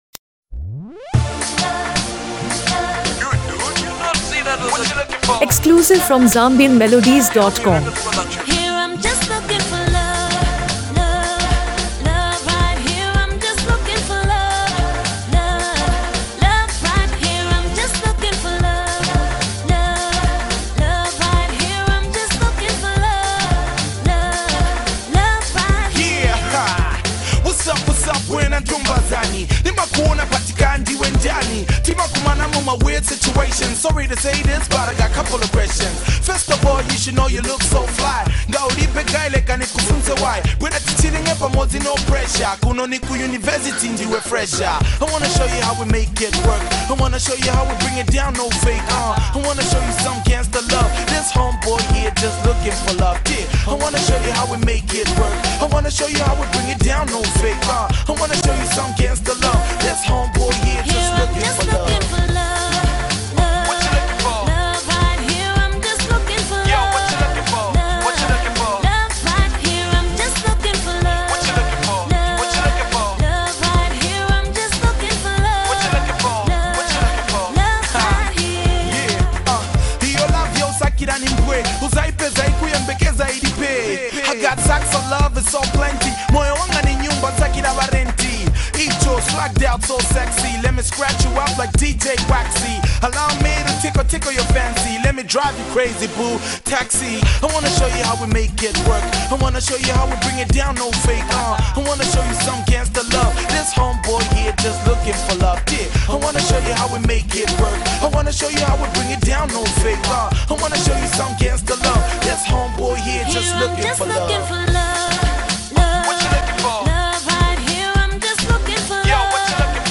smooth and melodic vocals